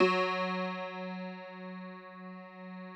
53r-pno08-F1.aif